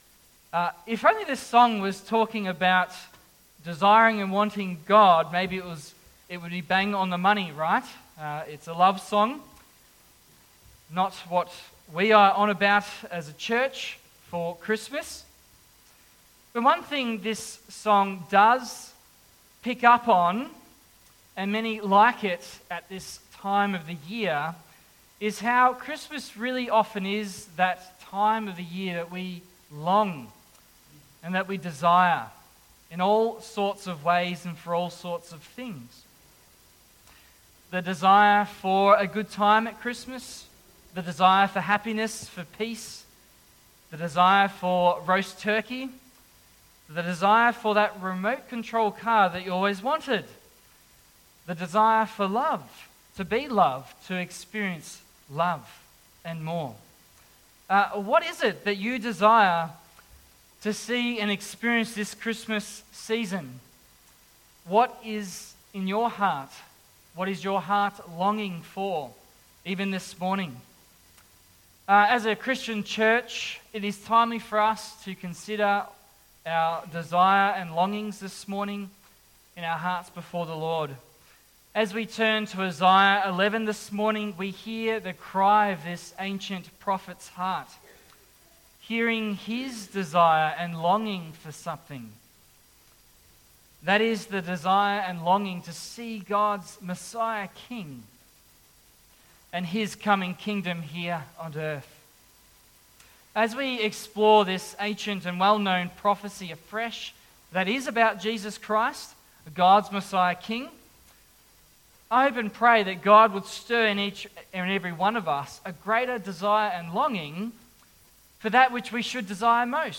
Service Type: AM Geelong Christian Reformed Church